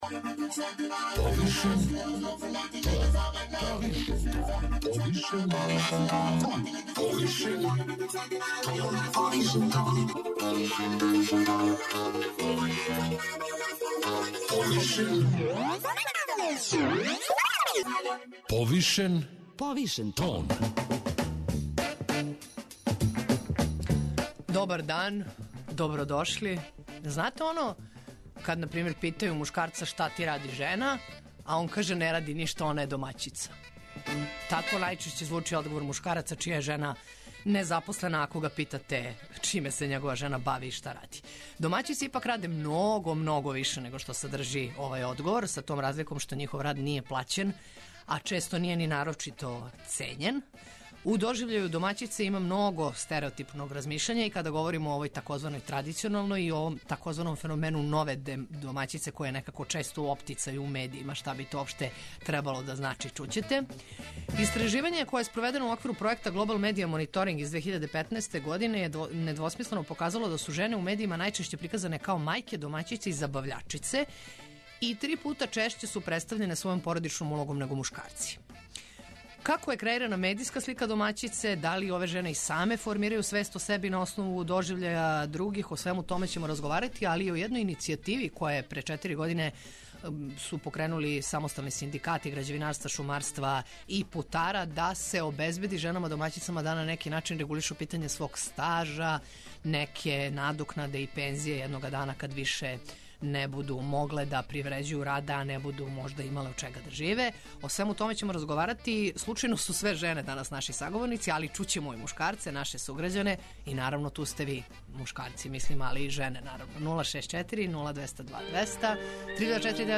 Чућете, наравно и шта кажу жене и мушкарци, било да се изјашњавају као домаћице и домаћини или на неки други начин.